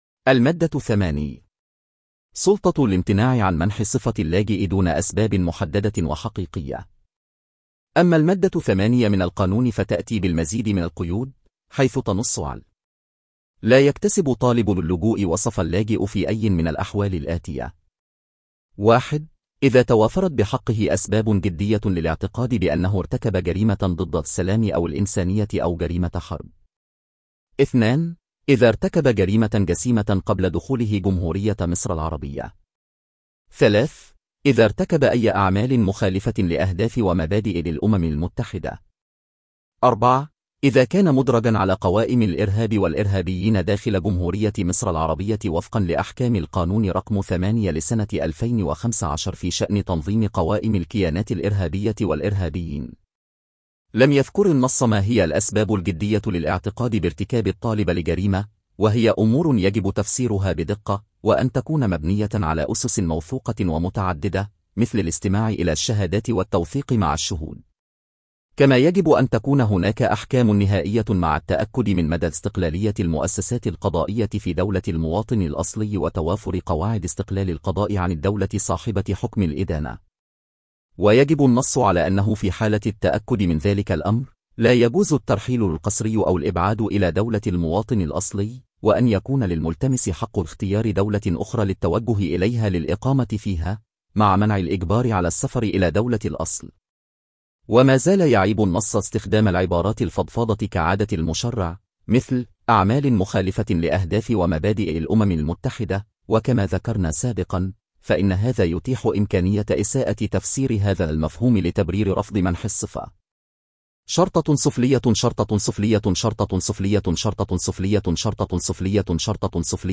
تنويه: هذه التسجيلات تمت باستخدام الذكاء الاصطناعي